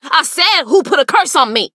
BS_juju_hurt_vo_10.mp3